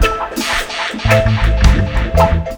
DUBLOOP 02-L.wav